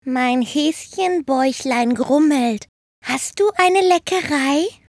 Synchron – Comic – kleines, niedliches Tier